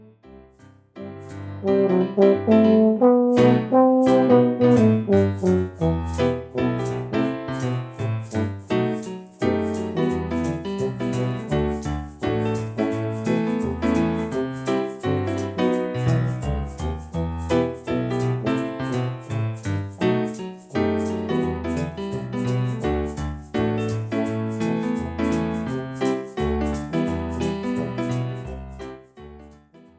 an album full of instrumental compositions and arrangements
Enjoy Jewish musical sounds from around the world.